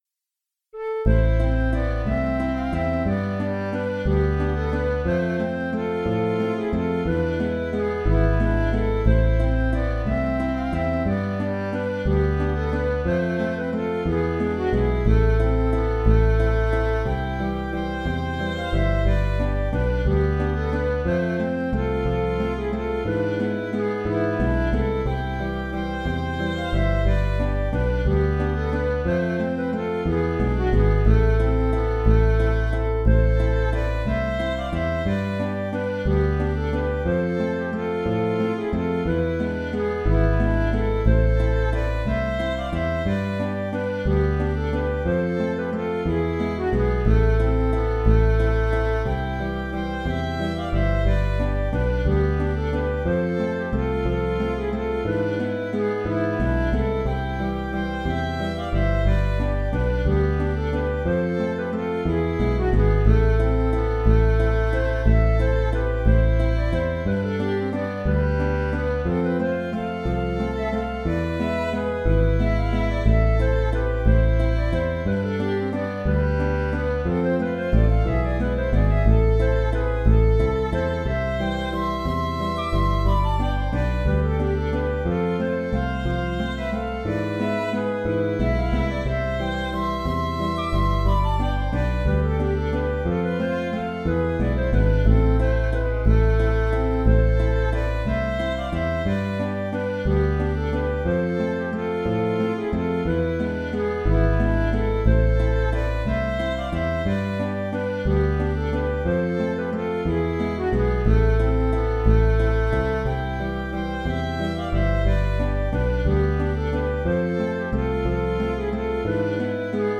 Greensleeves (Valse) - Musique irlandaise et écossaise
Trad. Anglais
Je propose deux contrechants à alterner, pour varier un peu le côté assez répétitif du thème (comme le plus souvent en musique « trad ».
Dans le fichier audio, le contrechant utilisé après la variation n’est que la reprise du premier.